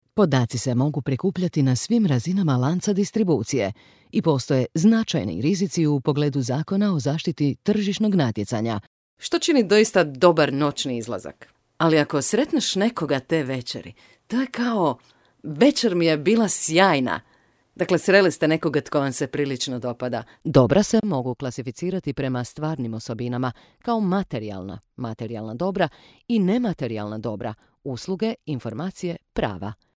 Kein Dialekt
Sprechprobe: eLearning (Muttersprache):